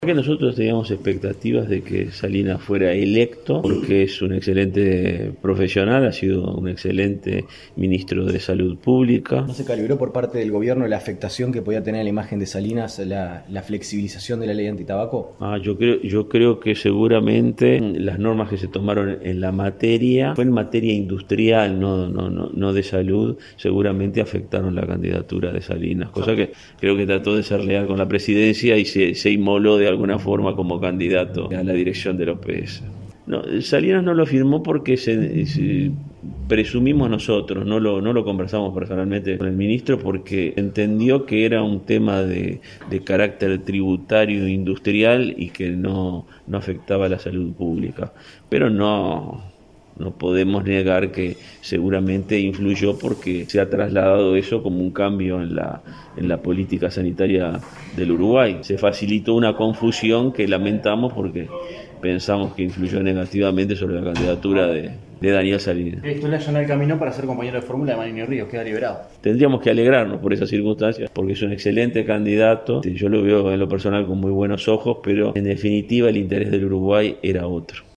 Pensamos que influyó negativamente en la candidatura de Daniel Salinas”, declaró Domenech en rueda de prensa.